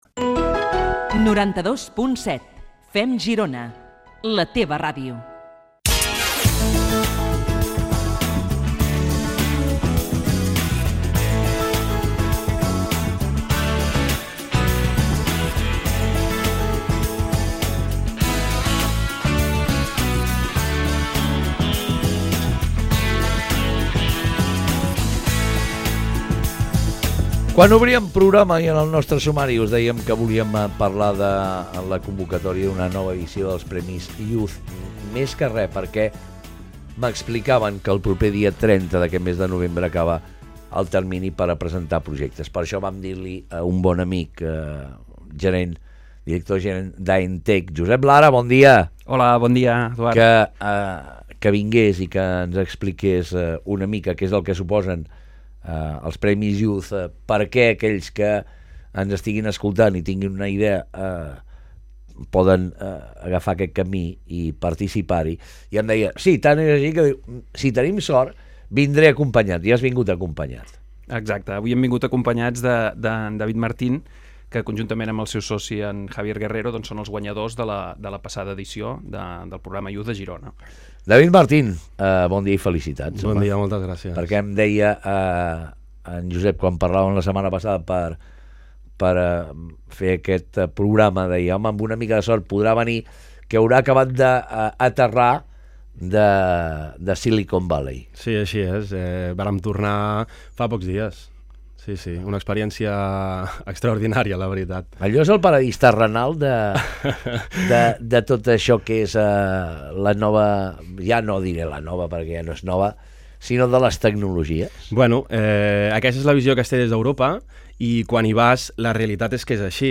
Entrevista a la ràdio FeM Girona - GM Cloud Design v3
Aquest passat 23 de novembre del 2015 ens han entrevistat al programa de ràdio Girona ARA de l’emissora FeM Girona.